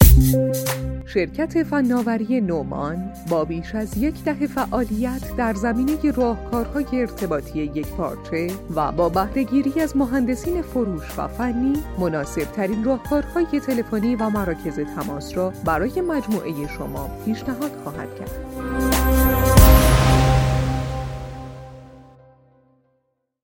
کد 205 ضبط صدای تلفن گویا